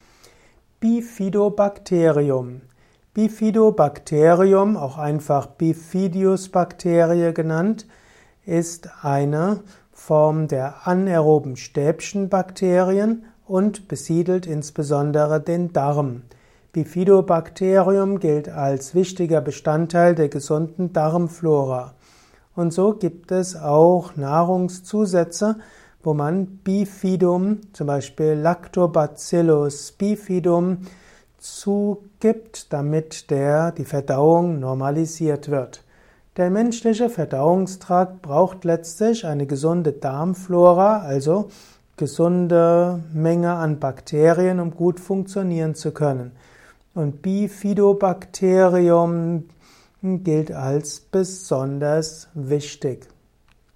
Kompakte Informationen zum Thema Bifidobacterium in diesem Kurzvortrag